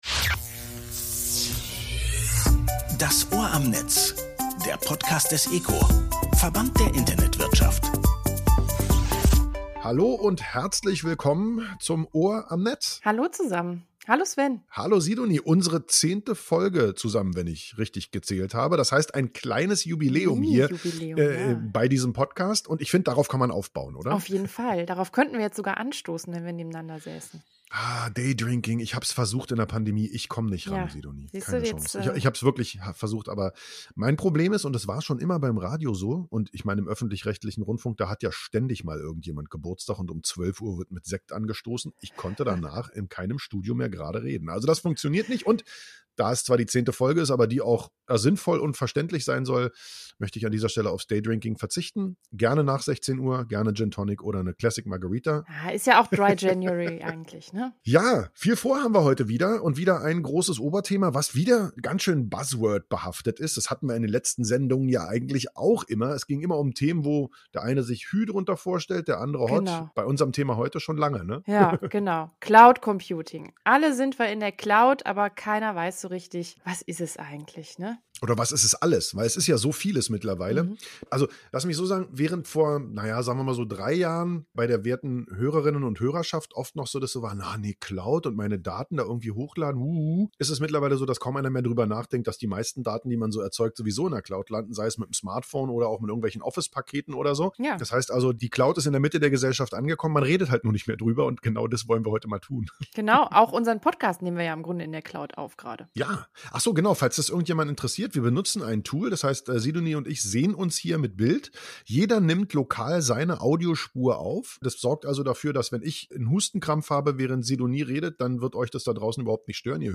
beschäftigt sich mit nachhaltigen und effizienten Cloudangeboten und gibt im Interview Tipps